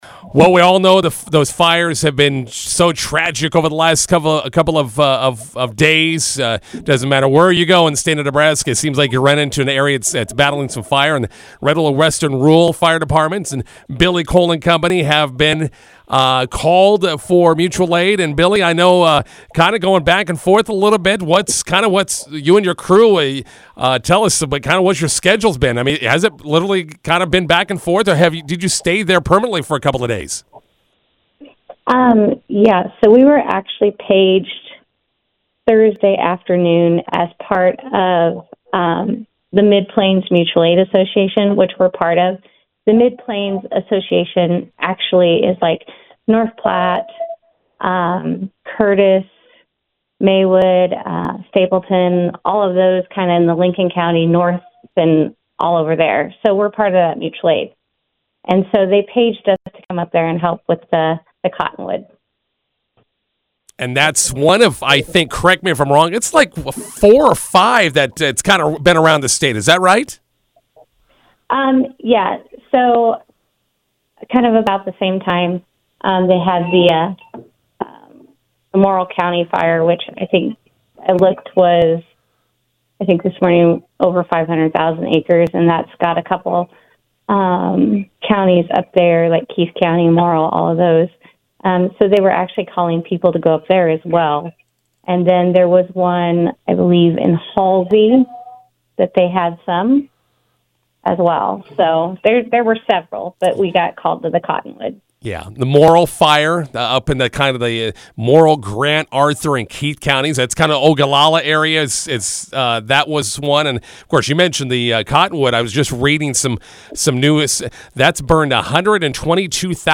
INTERVIEW: Red Willow County Western Rural joining other area fire departments in helping battle the latest fires.